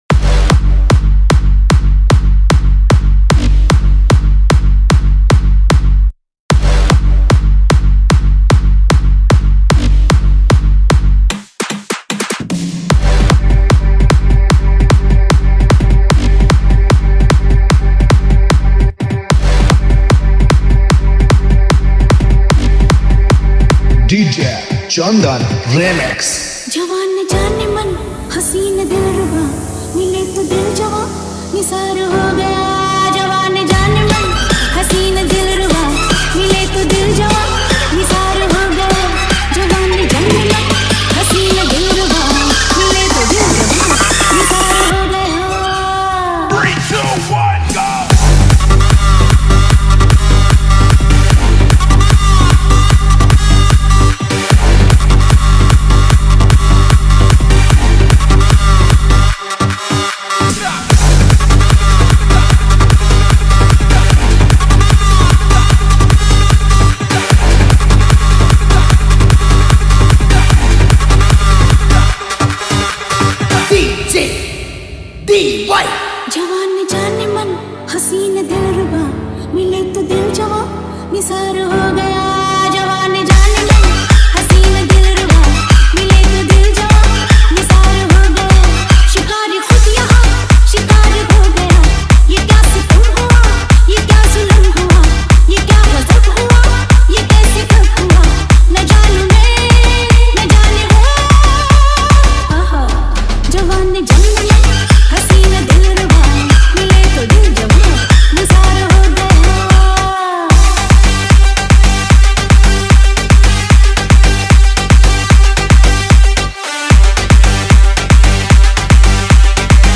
Category : Odia Remix Song